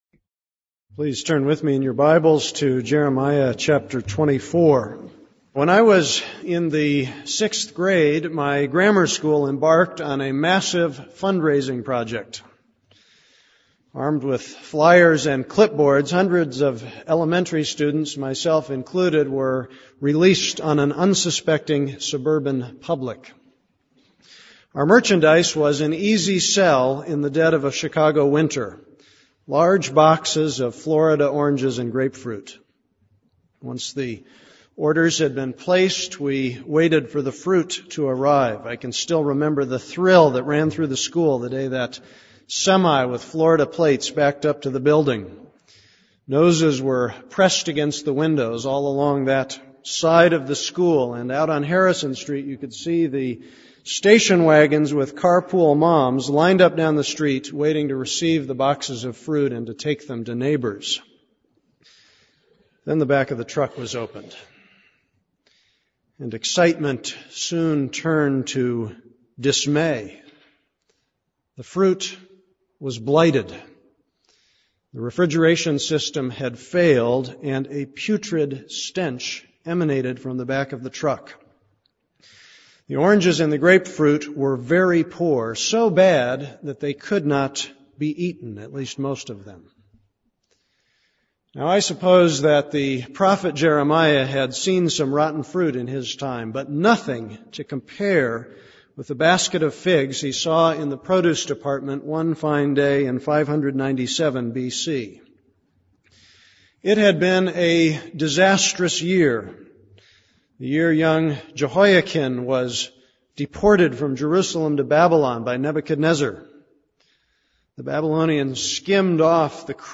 This is a sermon on Jeremiah 24:1-25:14.